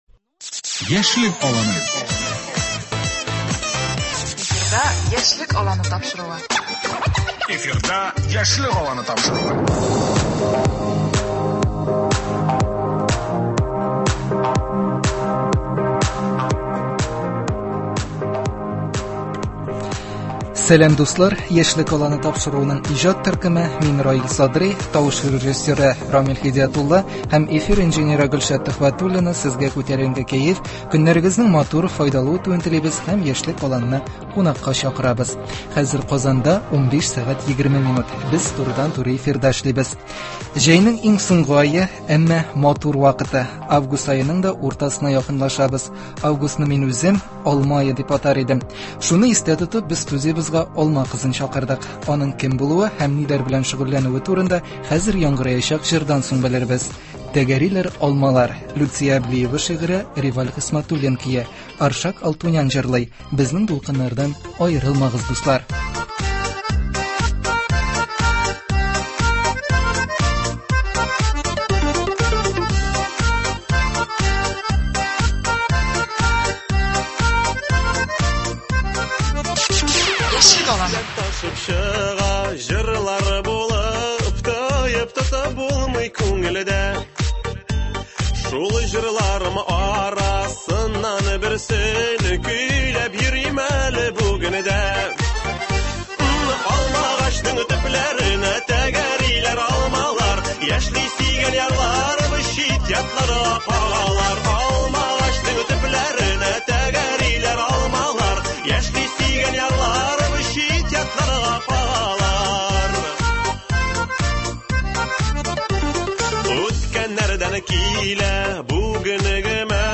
Шуны истә тотып без студиябезгә алма кызын чакырыдык. Аның кем булуы һәм ниләр белән шөгыльләнүе турында турыдан-туры эфирда булачак тапшыруда.